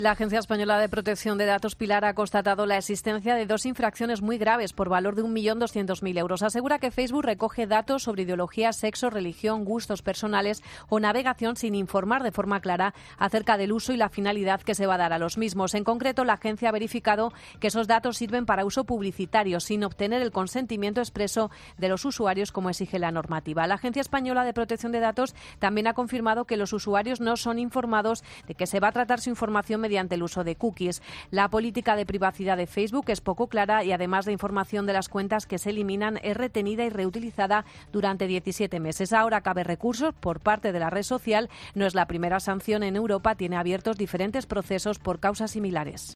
Multa millonaria a Facebook. Crónica